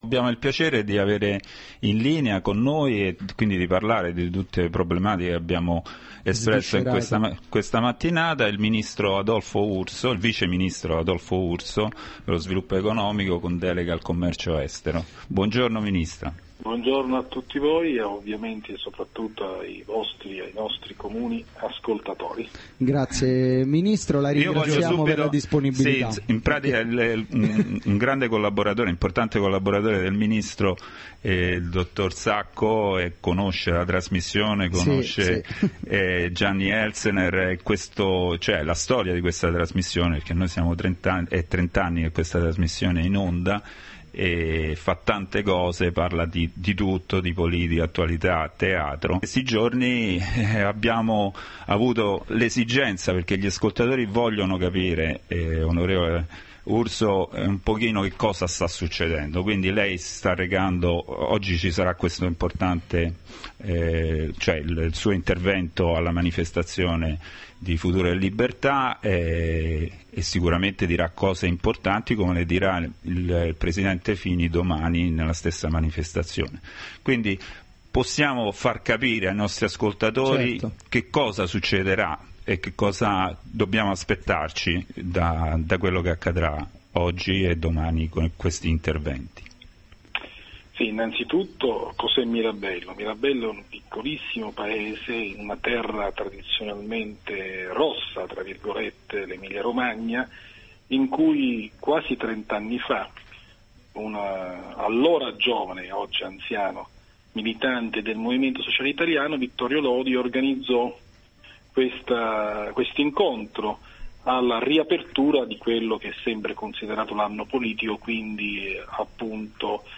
ascolta_lintervento_del_vice_ministro_adolfo_urso.mp3